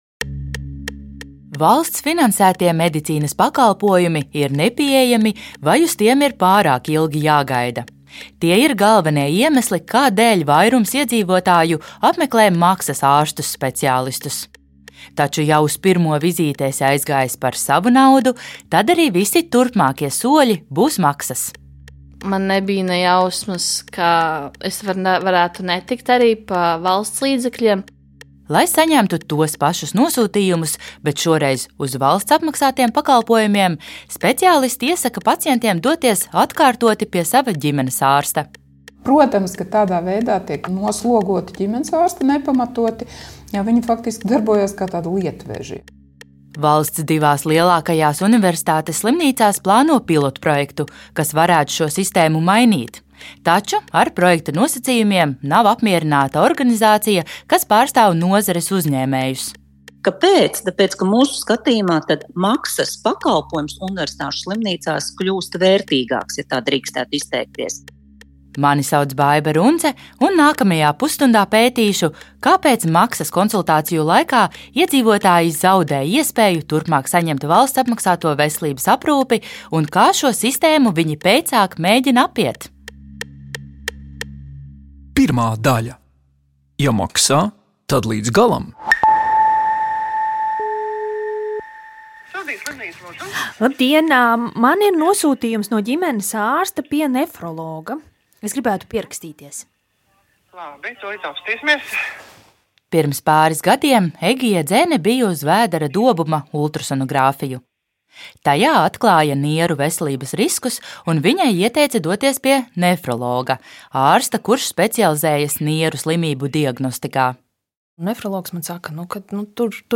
Katrs raidījums ir dokumentāls audiostāsts par procesiem un cilvēkiem, kas veido mūsu sabiedrisko dzīvi.